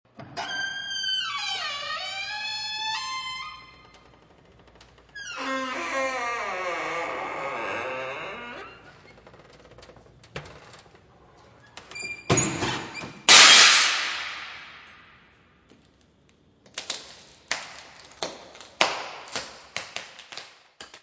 Angoise et epouvante : bruits et situations = Anguish and terror : noises and situations = Angustia y terror : ruidos y situaciones
Physical Description:  1 disco (CD) (ca. 63 min.) : stereo; 12 cm + folheto